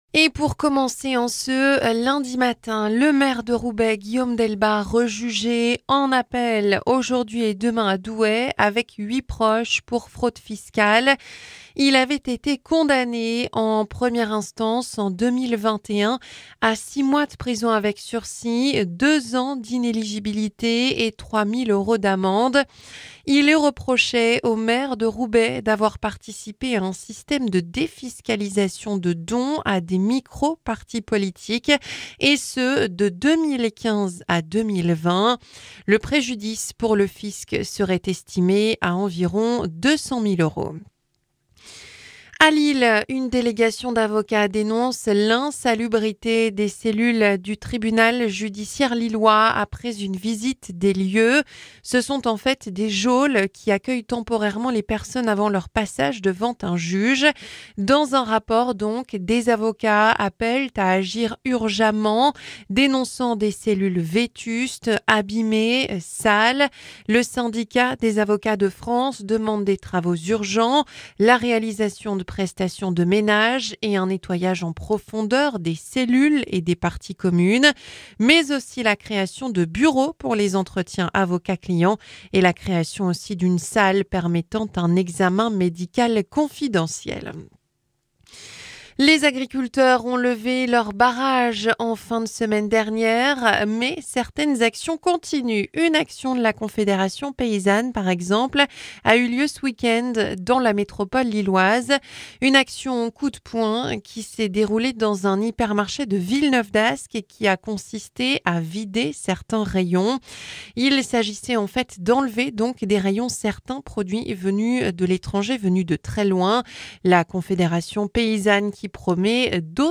Journal 9h - Le maire de Roubaix jugé en appel à partir d'aujourd'hui